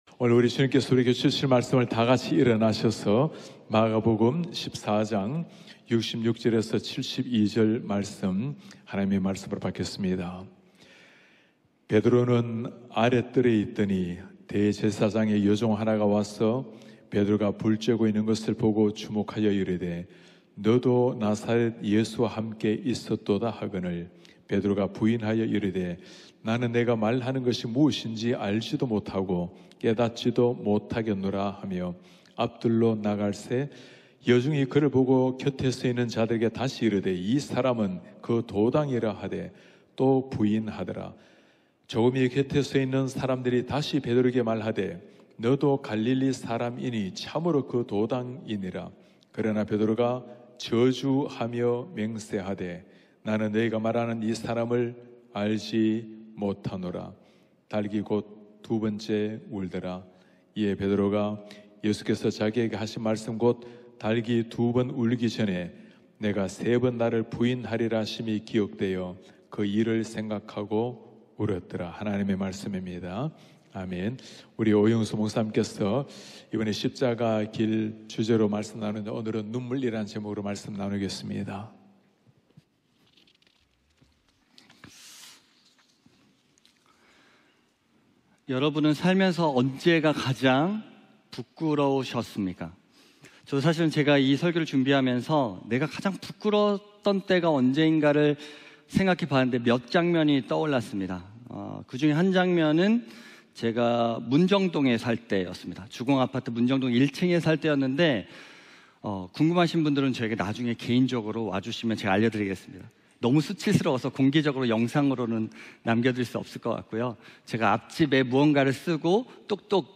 예배: 특별 집회